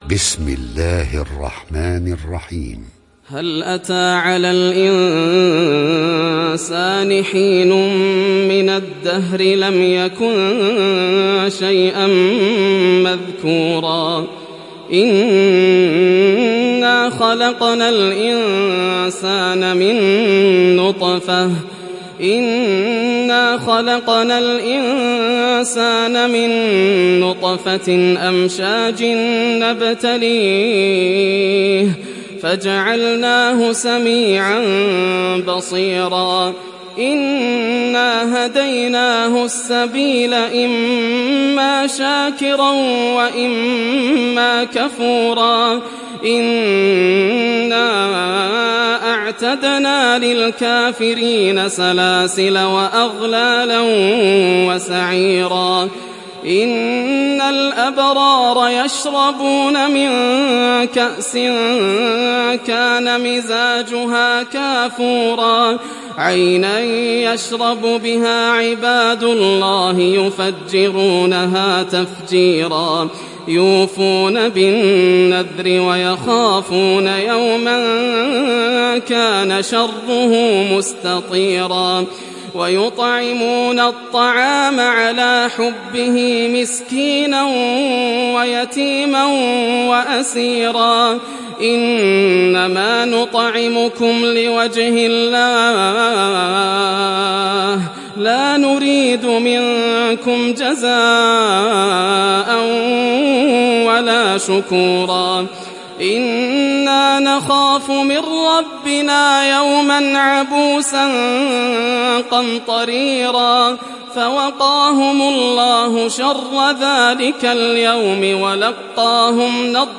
تحميل سورة الإنسان mp3 ياسر الدوسري (رواية حفص)